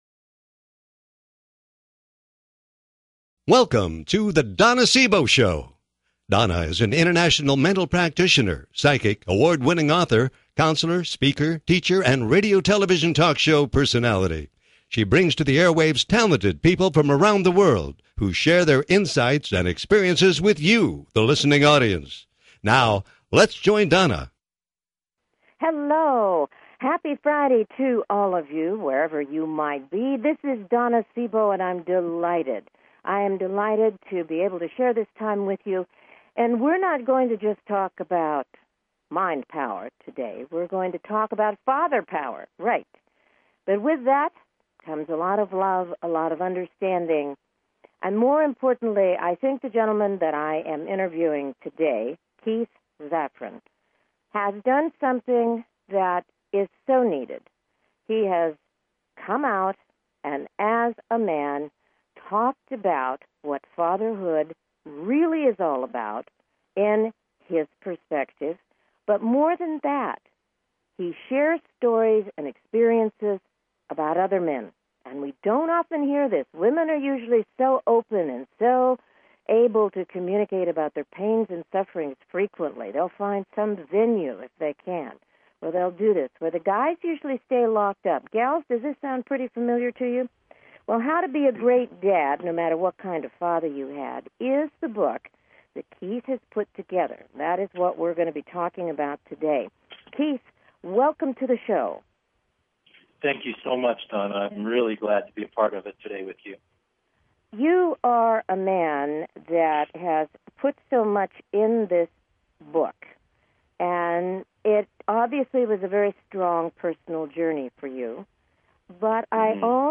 Talk Show Episode
Callers are welcome to call in for a live on air psychic reading during the second half hour of each show.